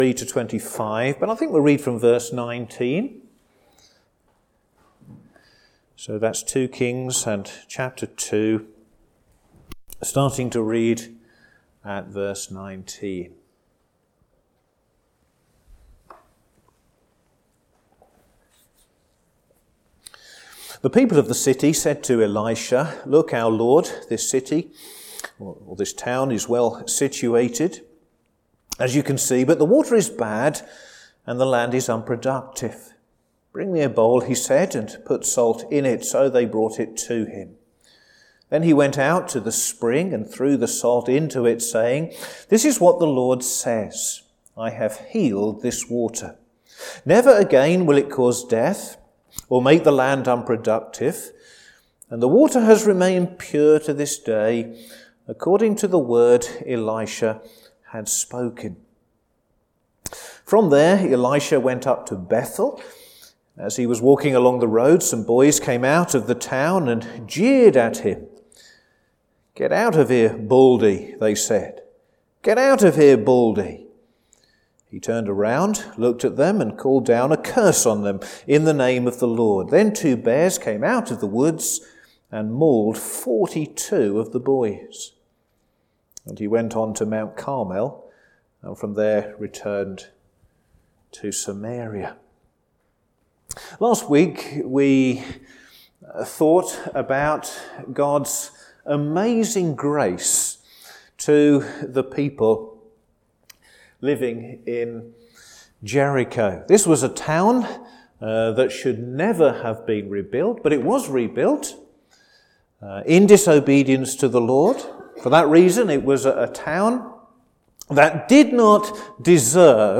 Sermon
Service Evening